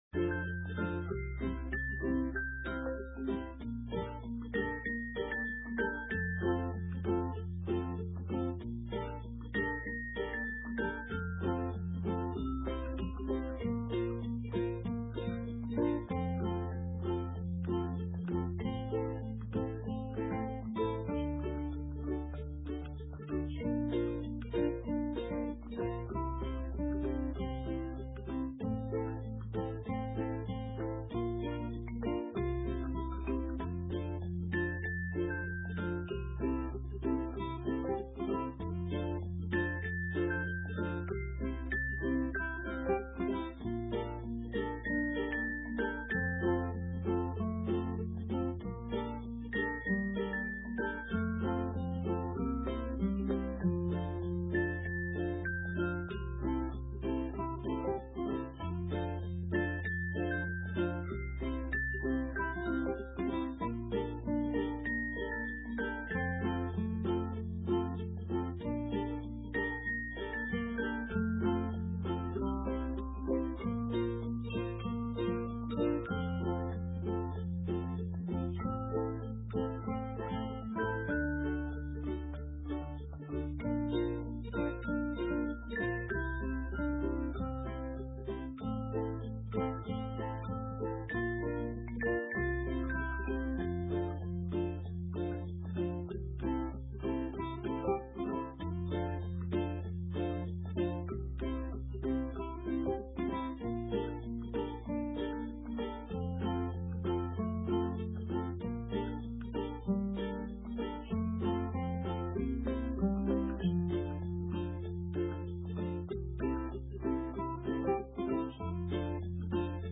Sermon:The good shepherd - St. Matthews United Methodist Church
The prelude begins at about 15 minutes into the recording
May 3, 2020 – The Fourth Sunday of Easter